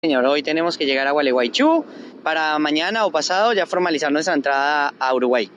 entrevistando